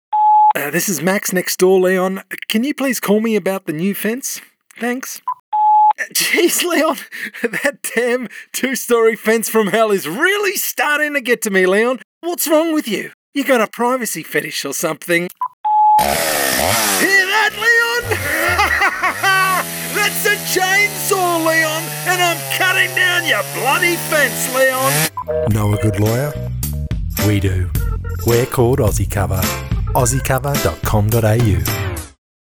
Character - AussieCover_Promo
Middle Aged
I operate from a professional-grade home studio, equipped with a RØDE NT1 microphone and Logic Pro, delivering high-quality audio tailored to your project’s specs — fast, clean, and professional.
Aussie Cover_FINAL.wav